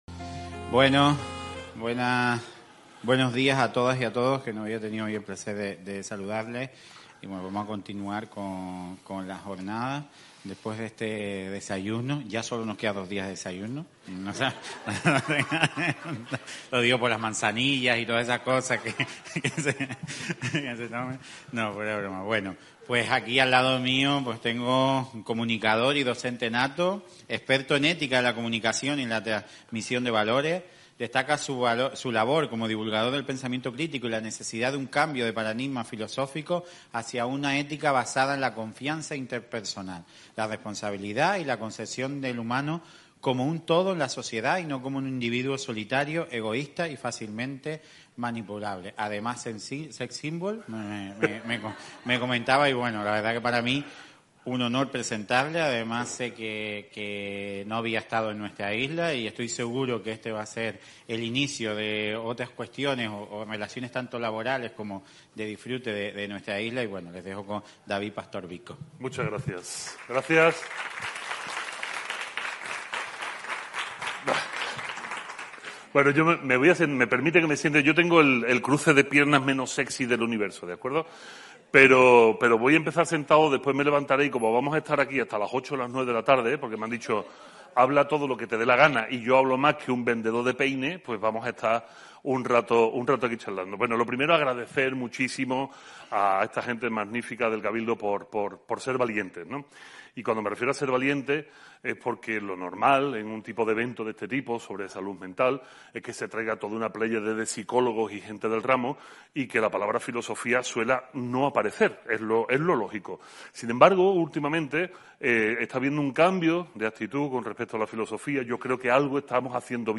Ponencia